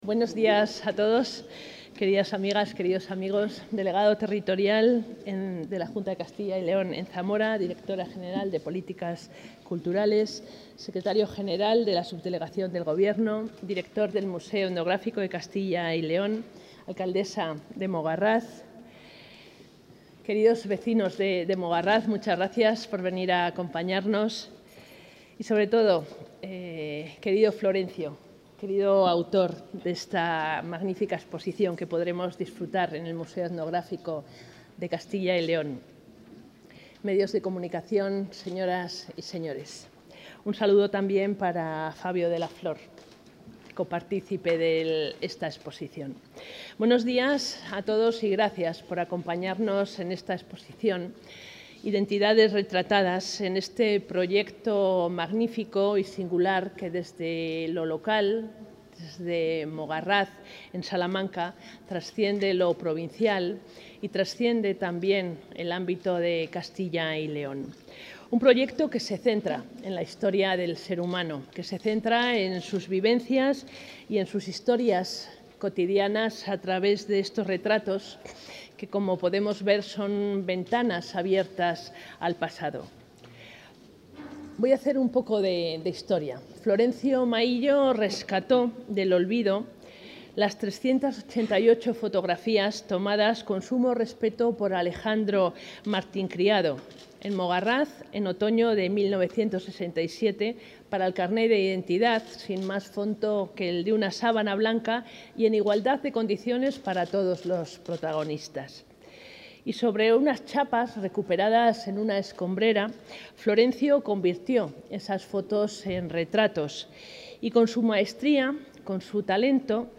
Consejera de Cultura y Turismo.